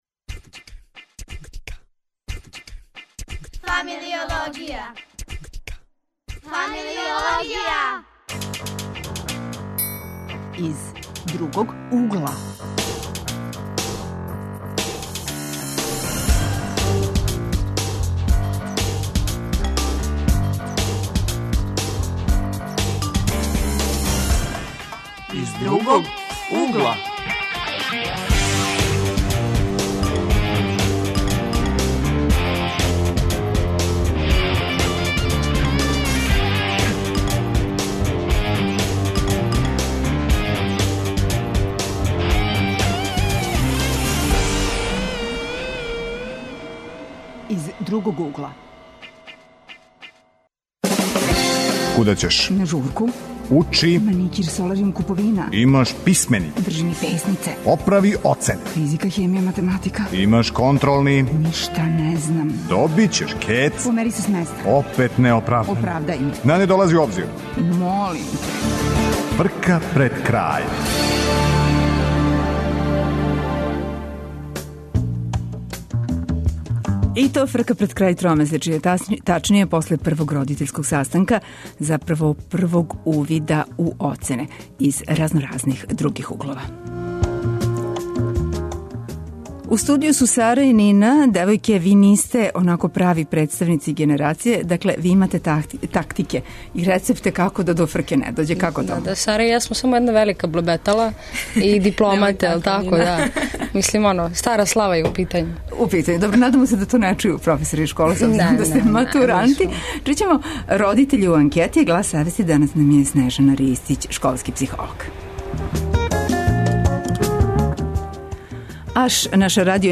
Крај првог тромесечја и гужва коју доноси тема је Другог угла. Гости су нам матуранти који имају рецепт да до гужве не дође.